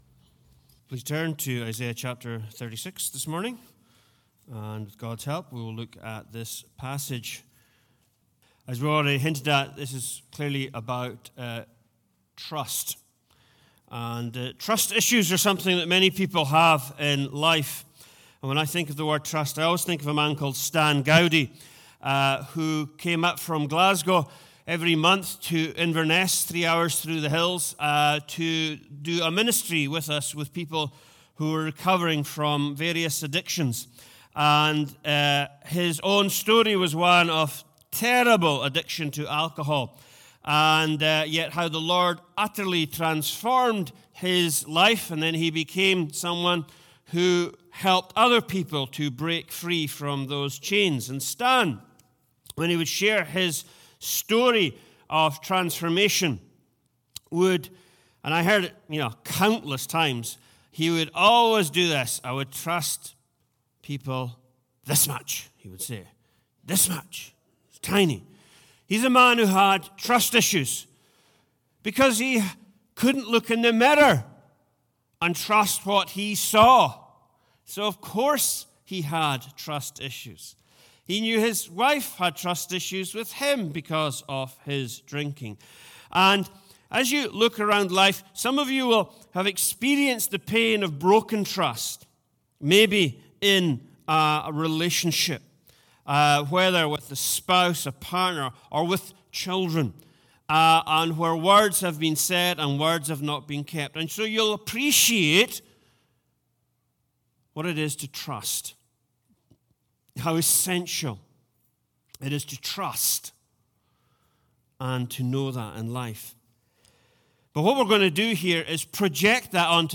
Join Hope Church to listen to the recordings of our Virtual Services.